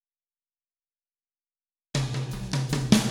Spaced Out Knoll Drums Intro.wav